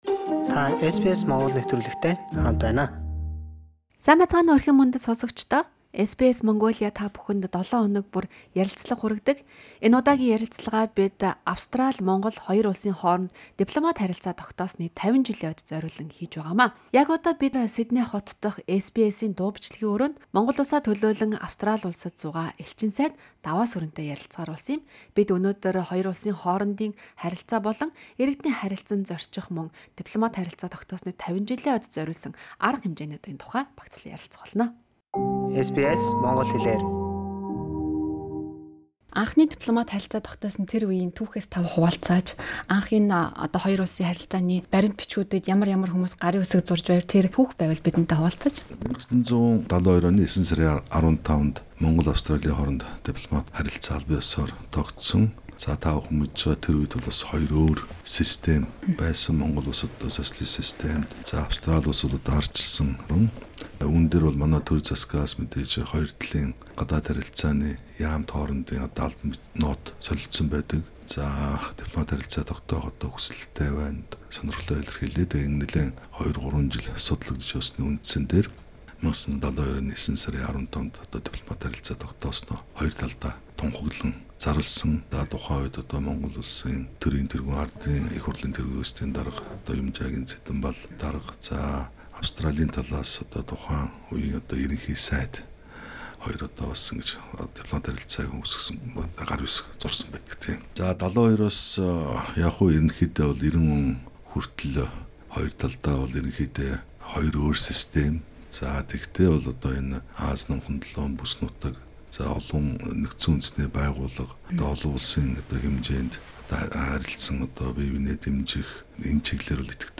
Хоёр улсын харилцааны анхны яриа хэлэлцээр хэрхэн явагдсан, гарын үсэг зурсан түүхэн өдөр, өргөжин тэлсэн 50 жилийн түүхийн зарим хэсгээс элчин сайдтай ярилцлаа. Мөн хамтын ажиллагааны чиглэл, худалдаа бизнес, иргэдийн харилцан зорчихтой холбоотой анхаарал татсан сэдвээр хариулт авлаа.